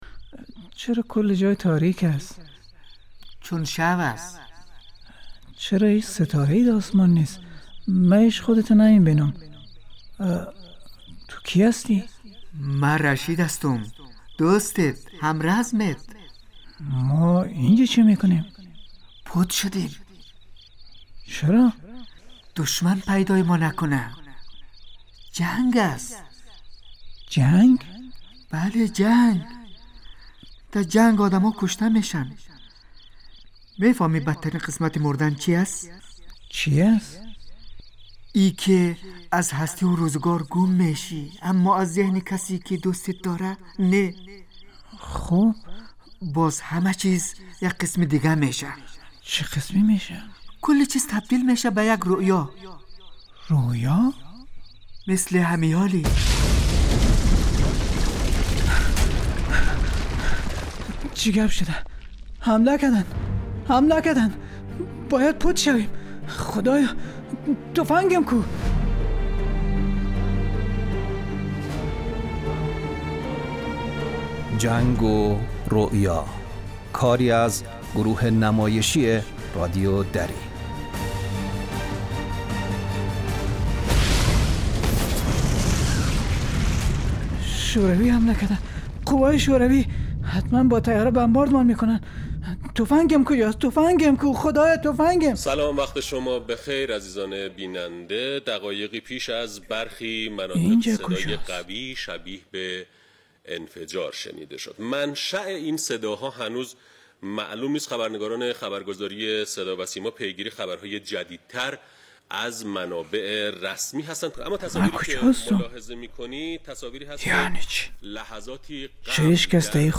این نمایش در رابطه با اثرات مخرب جنگ بر روحیه و روان مردم در سال های متمادی و همچنین نسل های مختلف می باشد.